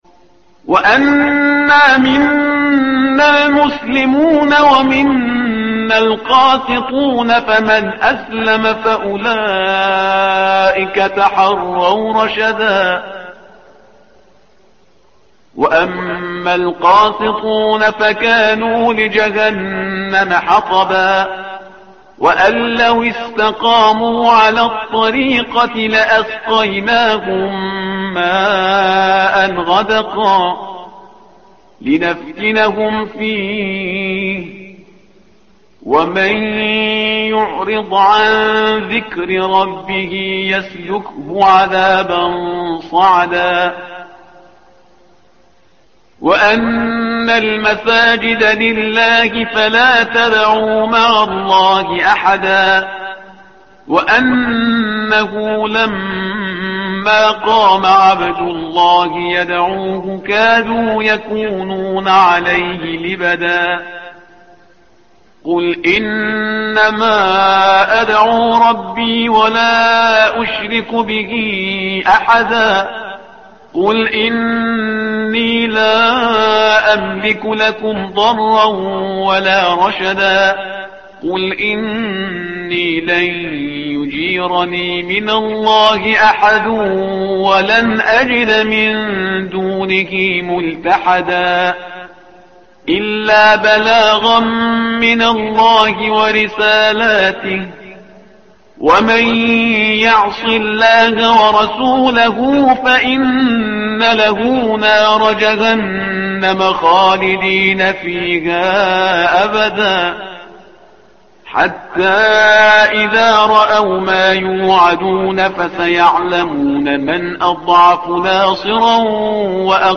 تحميل : الصفحة رقم 573 / القارئ شهريار برهيزكار / القرآن الكريم / موقع يا حسين